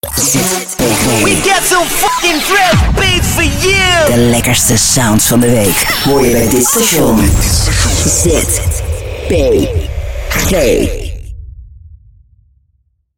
warm, enthousiastic, edgy, tongue and cheek, fresh, sexy, emphatic, strong, raw, young
Sprechprobe: Sonstiges (Muttersprache):